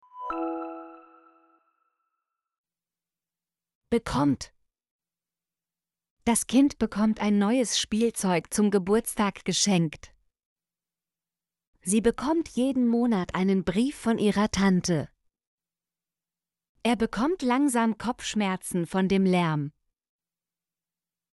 bekommt - Example Sentences & Pronunciation, German Frequency List